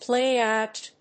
pláy óut